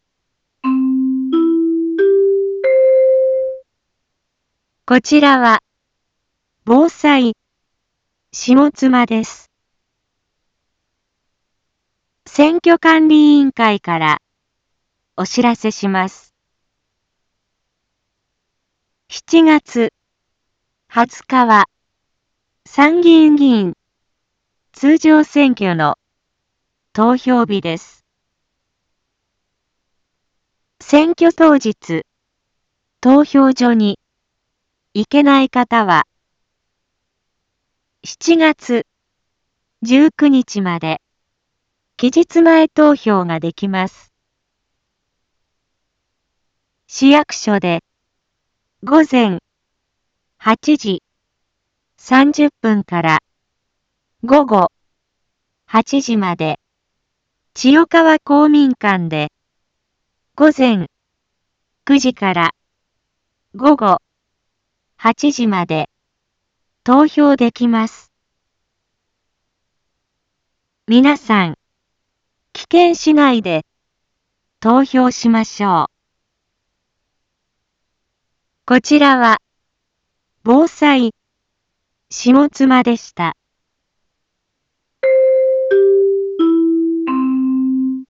一般放送情報
Back Home 一般放送情報 音声放送 再生 一般放送情報 登録日時：2025-07-13 13:01:47 タイトル：参議院議員通常選挙の啓発（期日前投票） インフォメーション：こちらは、ぼうさいしもつまです。